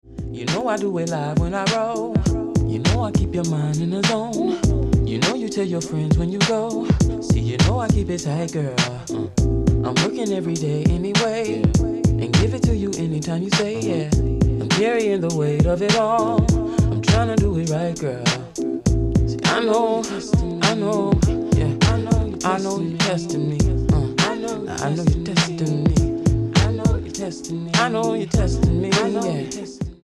R&B in 2002 didn’t get any better than this.